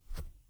grassy-footstep4.wav